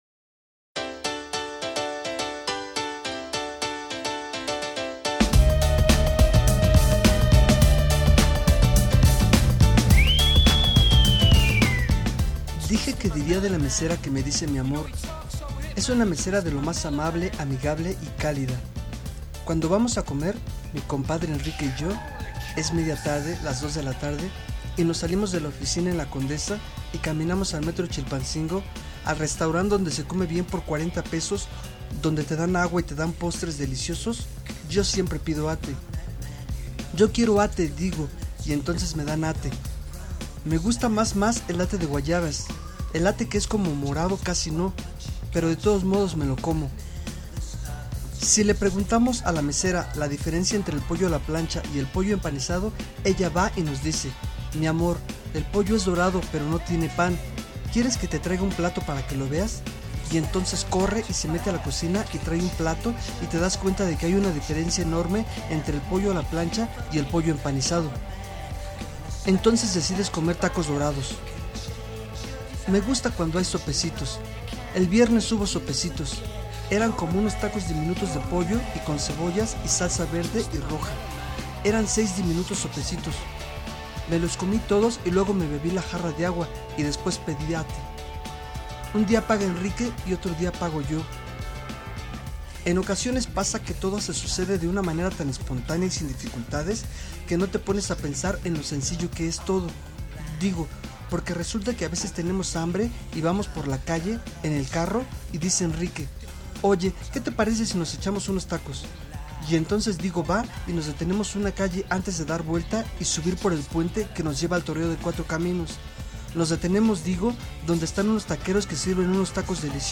Se escuchan mas nítidos: